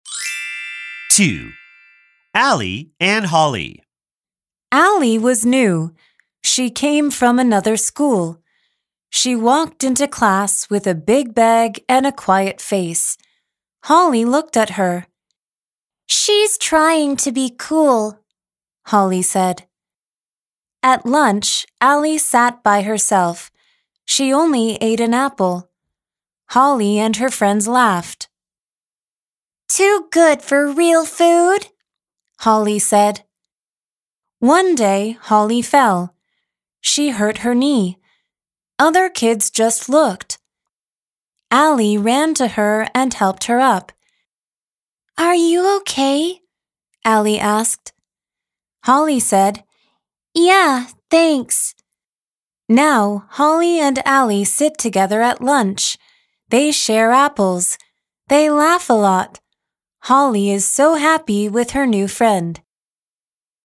每篇文章搭配鮮豔彩圖圖解，幫助讀者融入主題情境，輔助單字圖像記憶與吸收，同時附母語人士朗誦的專業發音MP3，搭配讀者可反覆聆聽，同時加強聽力能力。